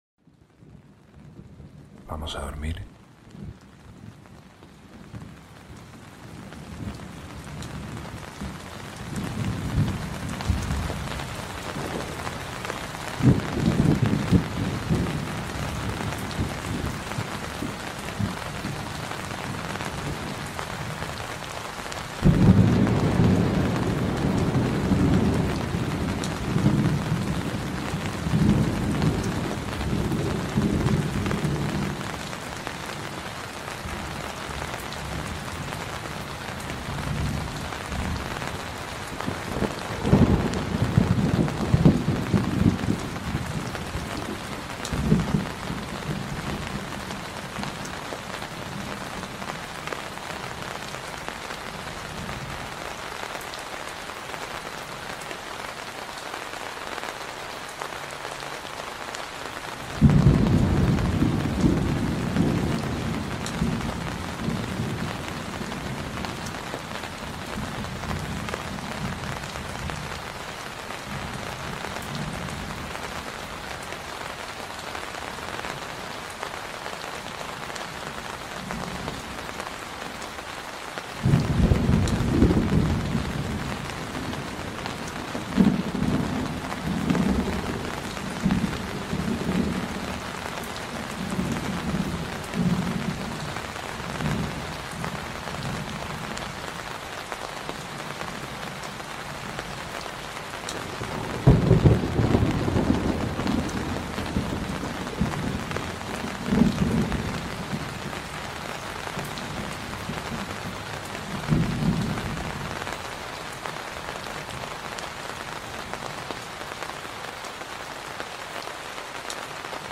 ASMR para dormir - Lluvia de verano sobre la carpa ⛺